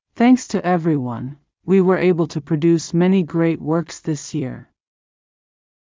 今回は例として、英語ナレーションの速度と高低の違いで、2タイプ作ってみたとします。
音声2（速度：0.5、高低：-4.8）
• ややゆっくり＆低めのトーンで、落ち着いたドキュメンタリー調の印象。